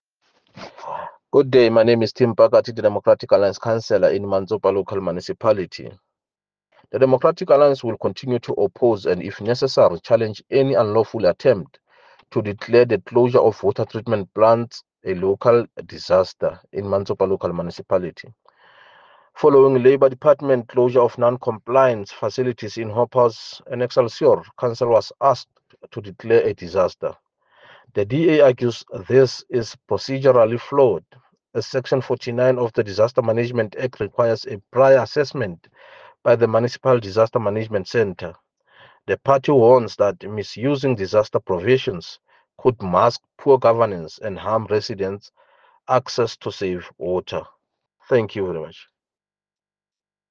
Sesotho soundbites by Cllr Tim Mpakathe and